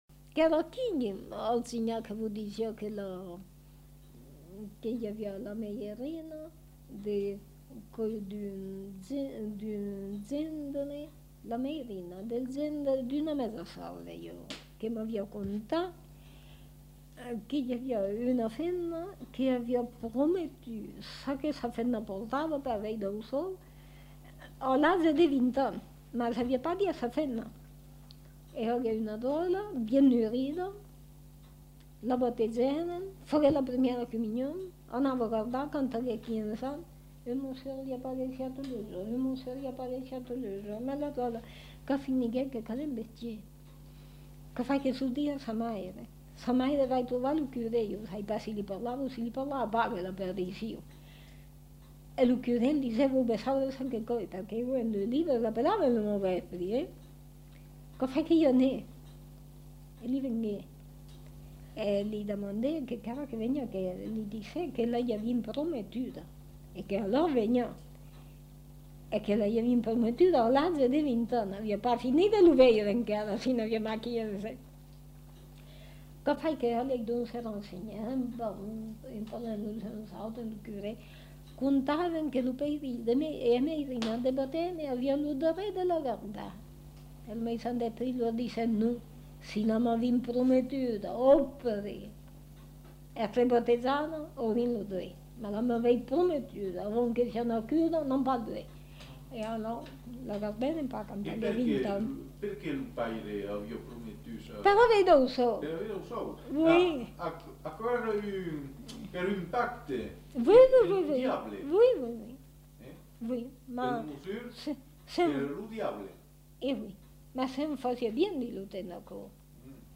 Aire culturelle : Périgord
Genre : conte-légende-récit
Effectif : 1
Type de voix : voix de femme
Production du son : parlé